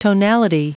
Prononciation du mot tonality en anglais (fichier audio)
tonality.wav